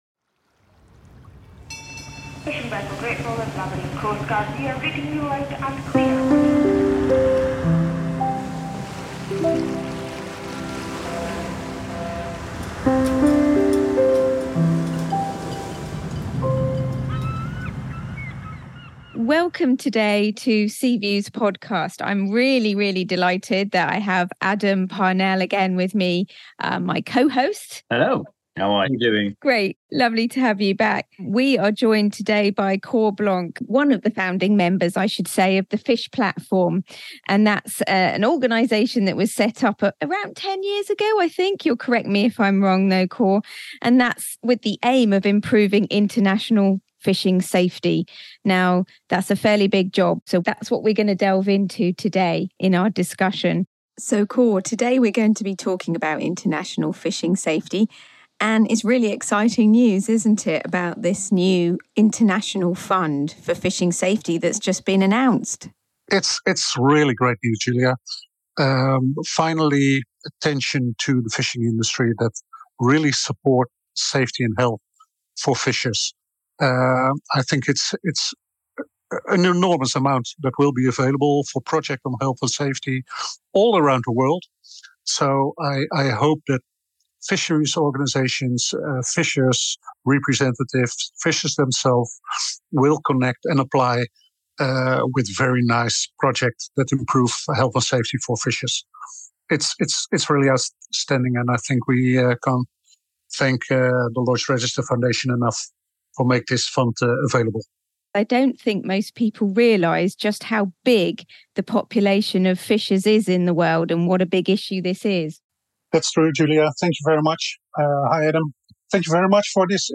From on-the-ground safety realities to the policy-level shifts required, this conversation is a rallying call to recognize and protect those who feed the world from the sea.